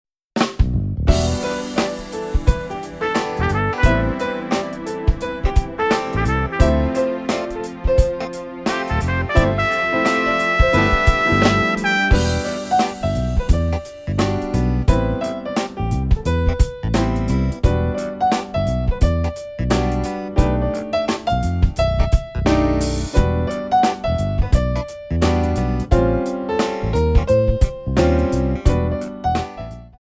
Hip Hop / Jazz
4 bar intro
Hip Hop shuffle